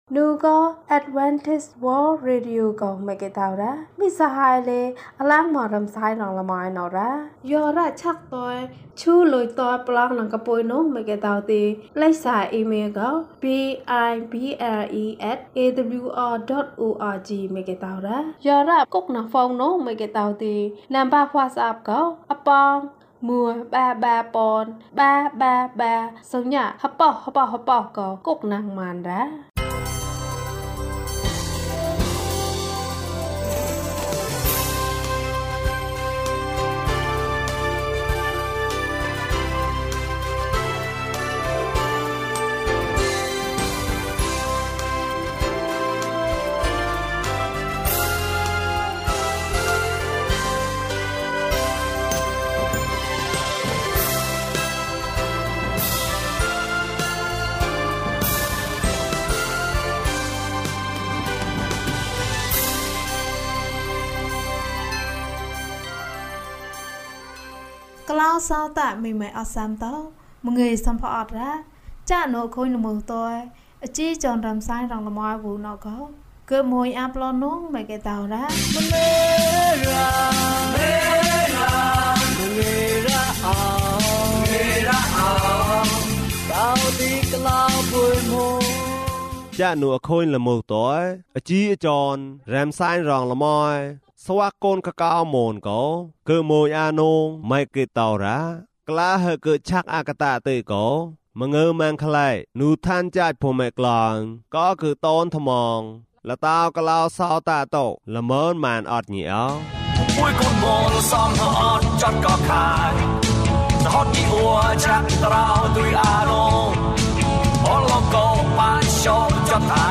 အပြစ်။၀၂ ကျန်းမာခြင်းအကြောင်းအရာ။ ဓမ္မသီချင်း။ တရားဒေသနာ။